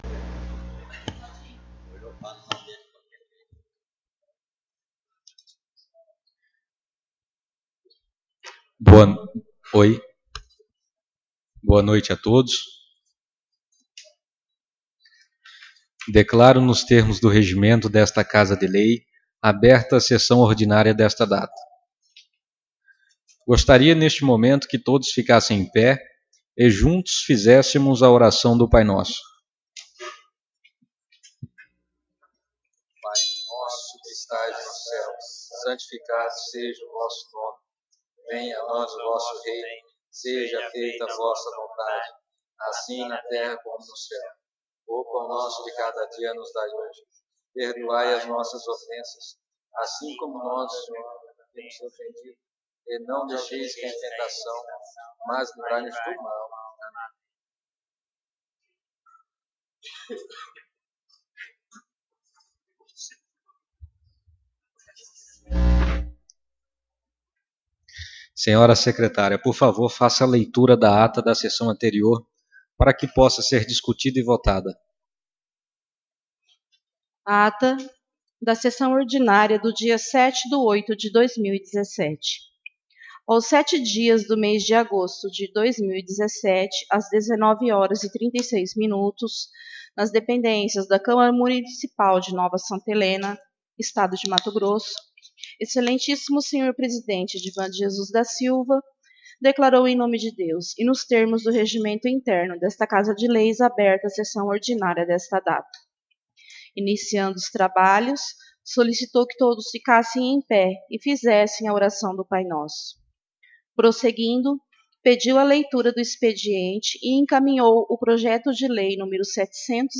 Sessão Ordinária 14/08/2017